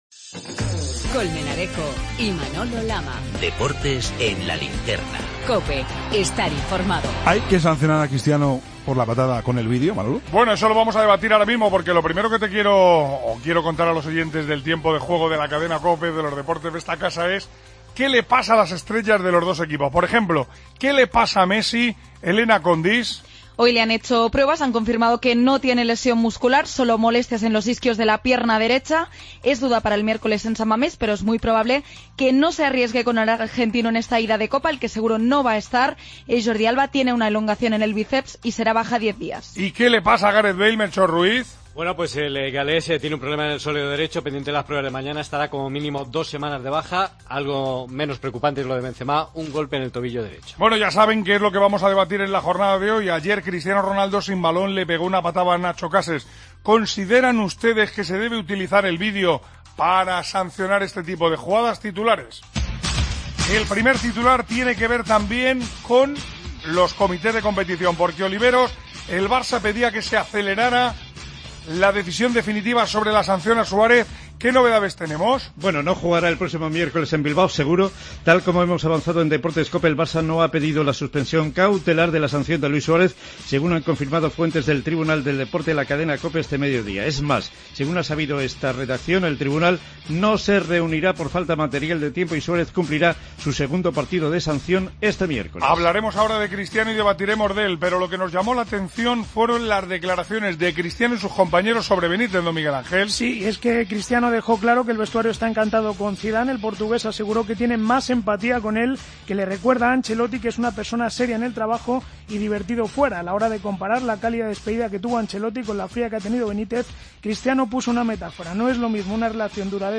El debate